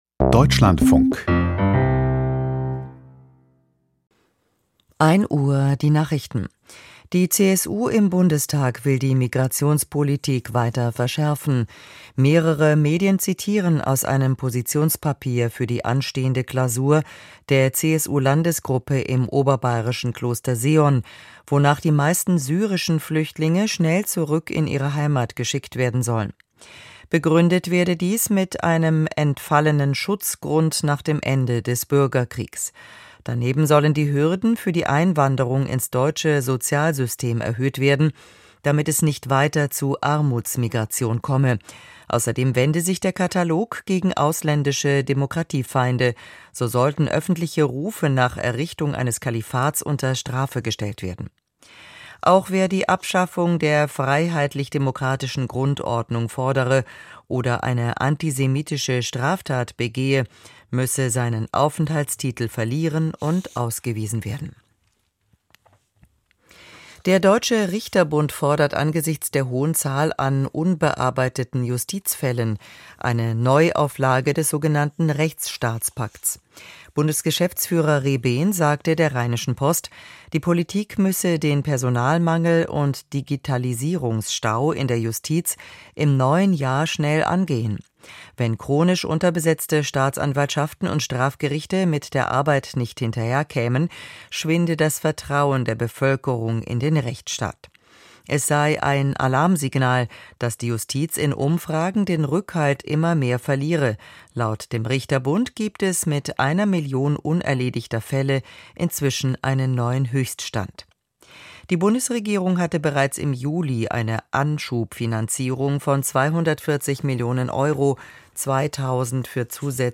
Die Nachrichten vom 02.01.2026, 01:00 Uhr